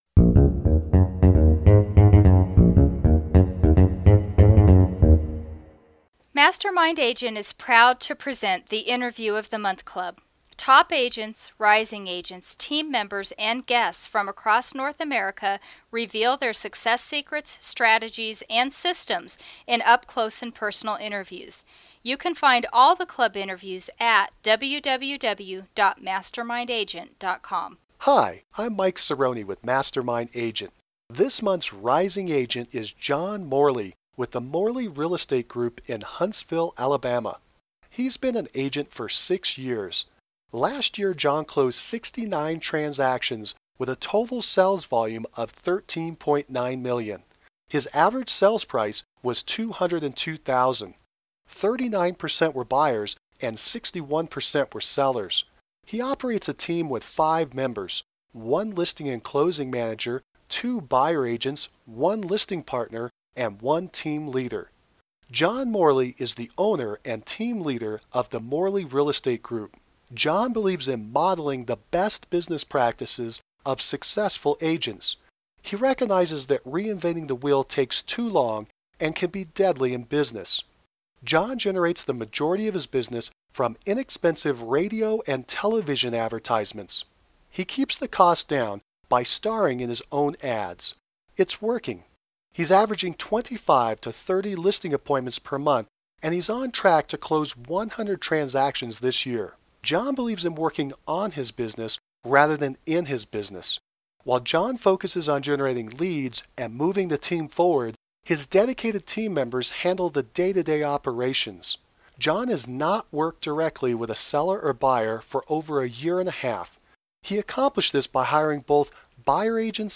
January 2011 Rising Agent Interview with